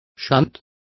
Complete with pronunciation of the translation of shunts.